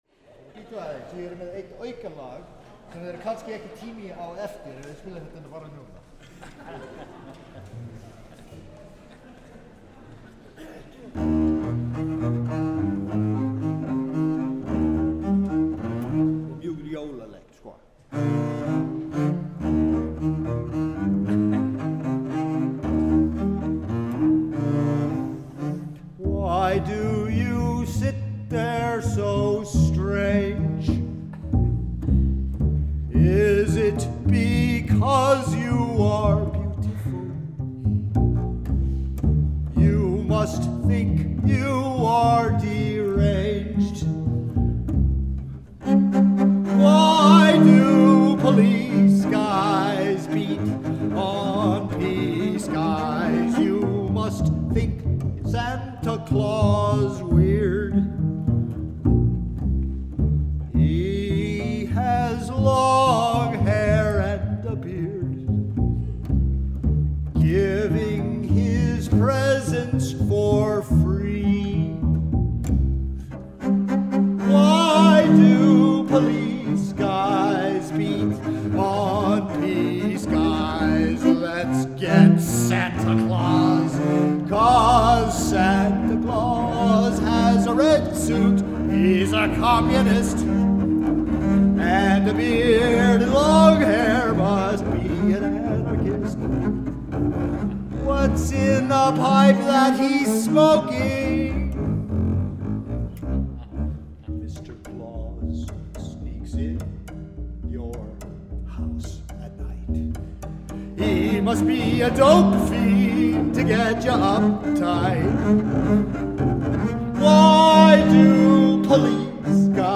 In this concert I decided to use AB setup located over the orchestra.
The only negative thing was a bit too long reverb on the orchestra and too short on soloists.
Most of the time they were far away from the best place for the mics so I got some phase failure.
Recorder: Sound Devices 744 w. 552 preamp
Mics: SE4400 in AB setup. 60cm apart in 2.5 meters over the orchestra. For bass soloist, SE 4400 in MS setup (mid mic omni). For tenor, SE1a in XY setup (not used in this web session).